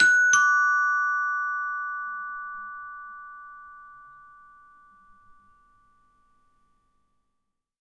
Door Bell Sound
household
Door Bell